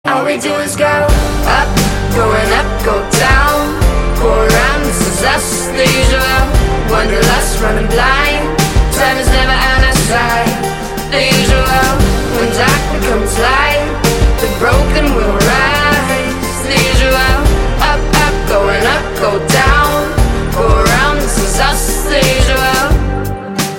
• Качество: 128, Stereo
поп
дуэт
красивый женский голос
alternative